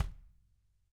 Index of /90_sSampleCDs/ILIO - Double Platinum Drums 1/CD4/Partition A/REMO KICK D